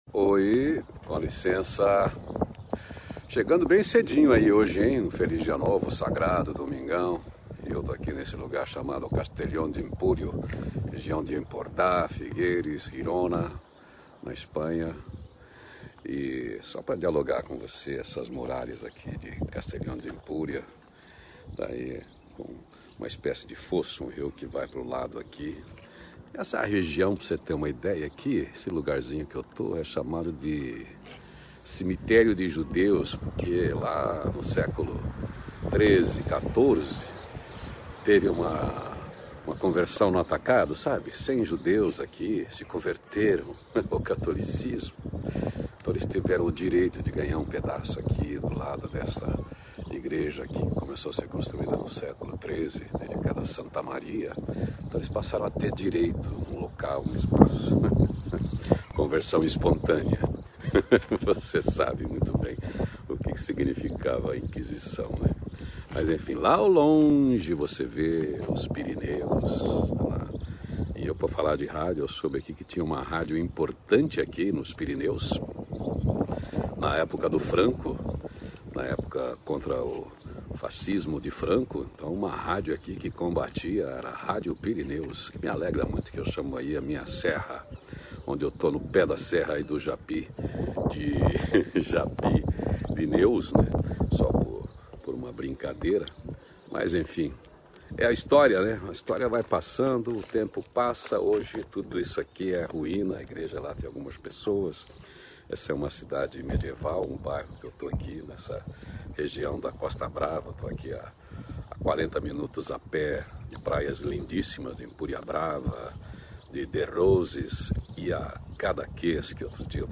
Uma prosa livre direto de Castelló d’ Empurie, Catalunha, Costa Brava, Espanha. Andando por um pueblo medieval.